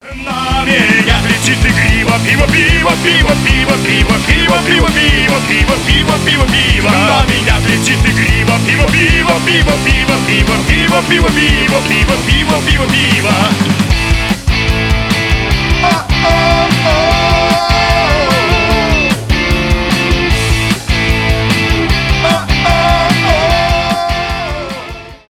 панк-рок , веселые